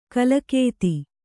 ♪ kalakēti